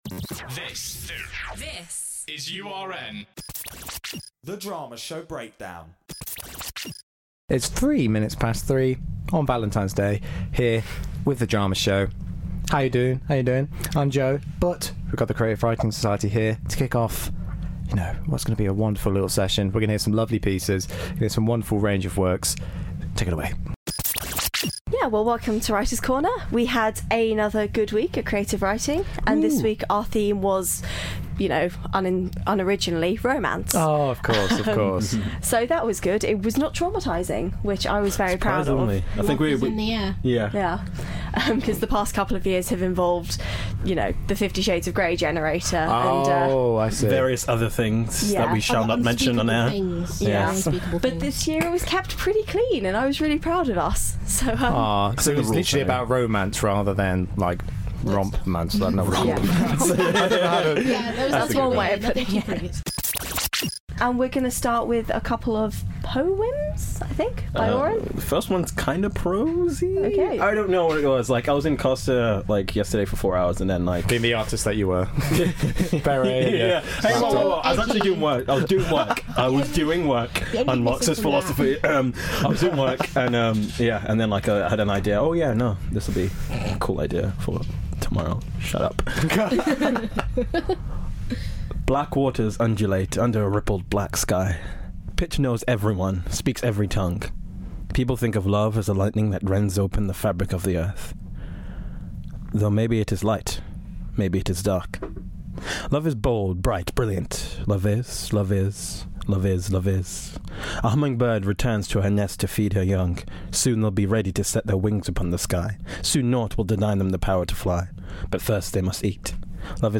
Listen in as the lovely Creative Writing Society join us once again, this week with pieces themed around love and romance in honour of Valentine's Day.